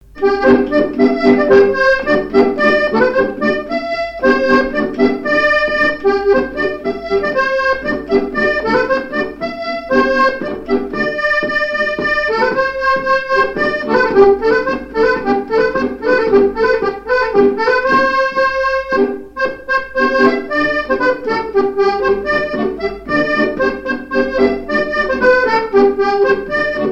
danse : ronde : grand'danse
airs de danse à l'accordéon diatonique
Pièce musicale inédite